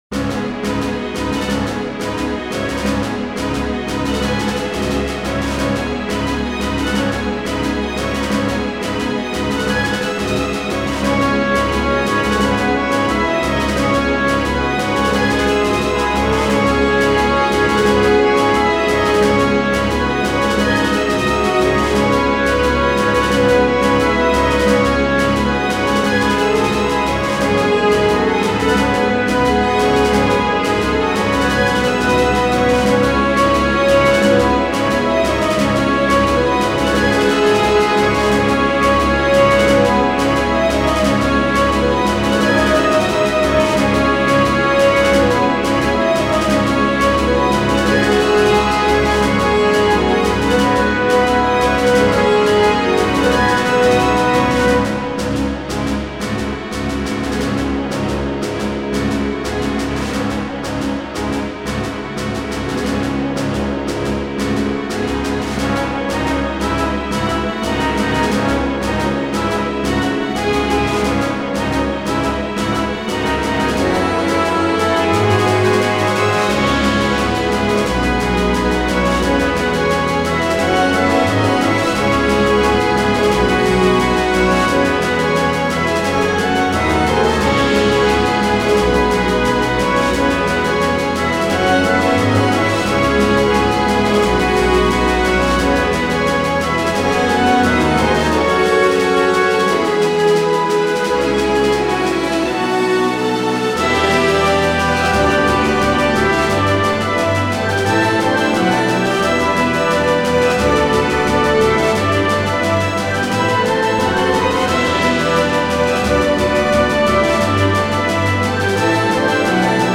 フリーBGM イベントシーン 熱い・高揚
フェードアウト版のmp3を、こちらのページにて無料で配布しています。